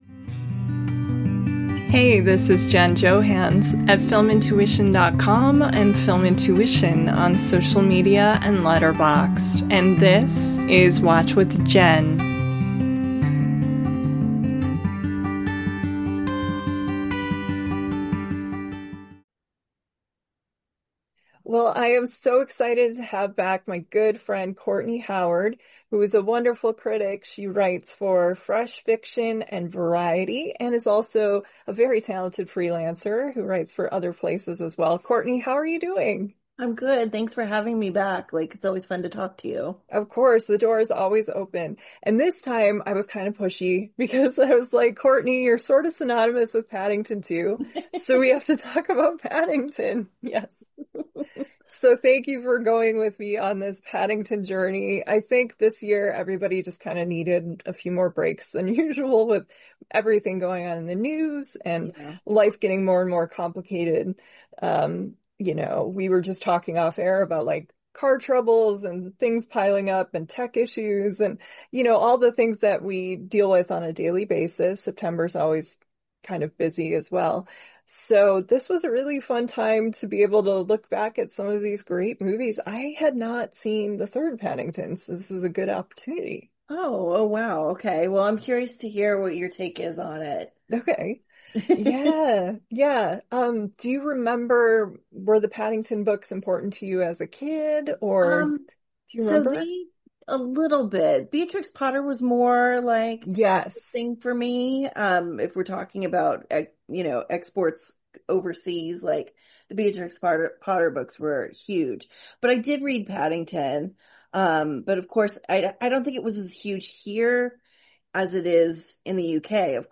a breezy, nostalgic, spirited, & informal chat about everyone's favorite Peruvian bear.